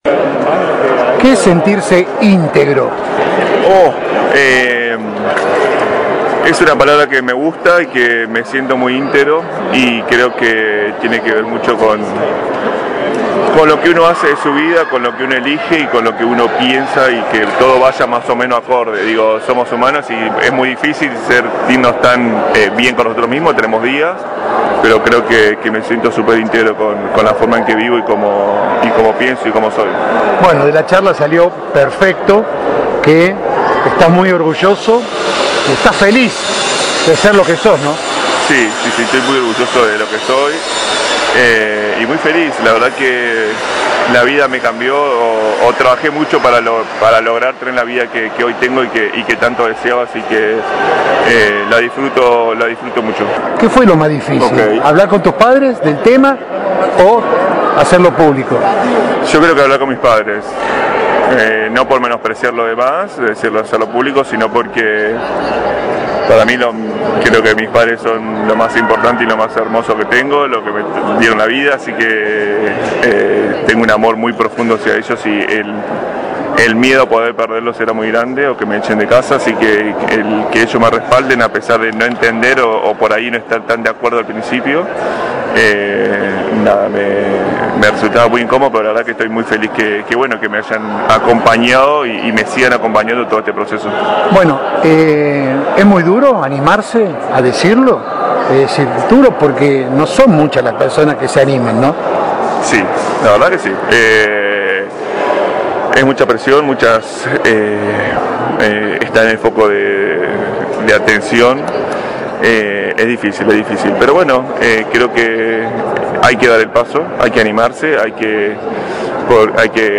Audio de la nota con SEBASTIÁN VEGA: